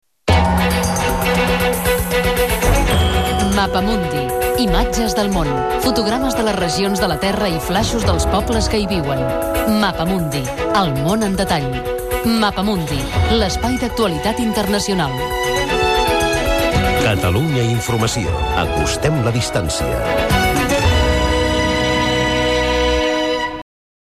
Promoció del programa "Mapa mundi".
FM